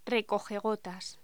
Locución: Recogegotas
Sonidos: Voz humana